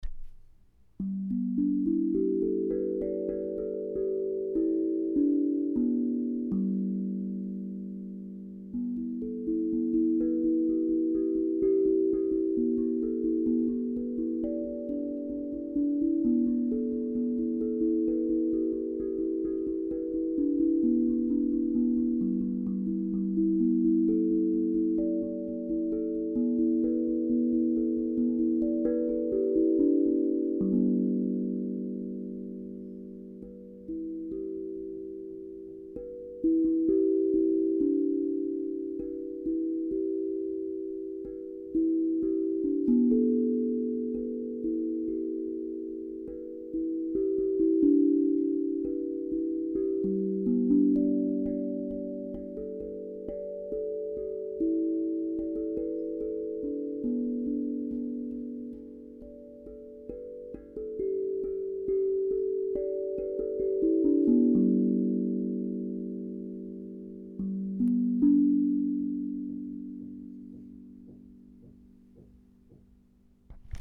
Cette gamme est puissante et joyeuse, facile à jouer, idéale pour tous, grands et petits Gravure de deux spirales entrelacées qui sont entourées de 8 triangles, 6 fleurs de lotus, 6 graines de puissances dans un mandala floral.
spirale-et-mandala-sol-joyeux-432-hz.mp3